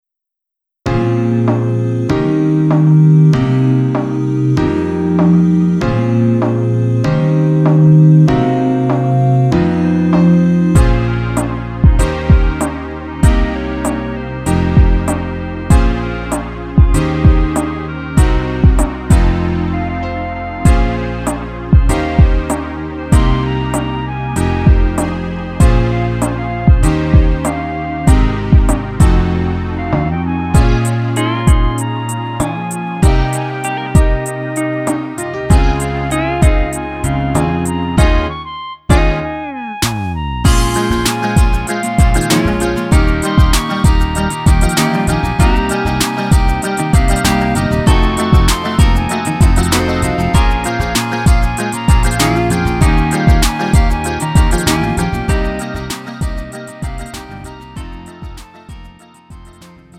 음정 -1키 3:35
장르 가요 구분 Lite MR